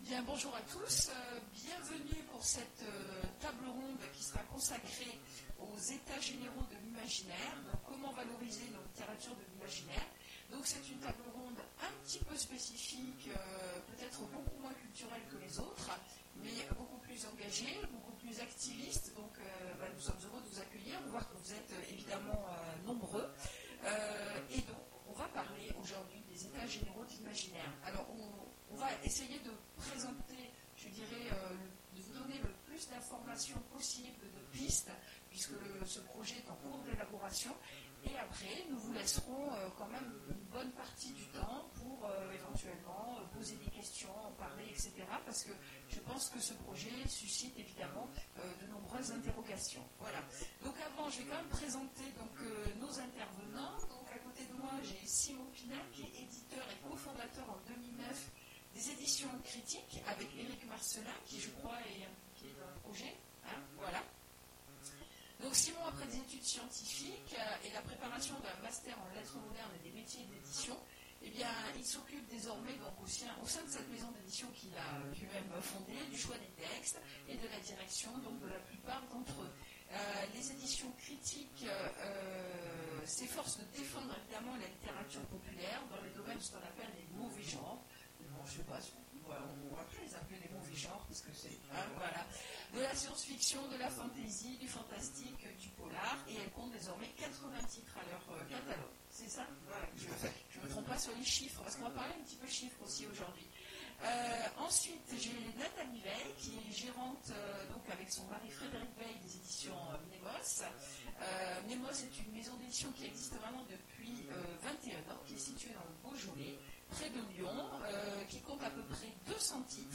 Mots-clés Edition Conférence Partager cet article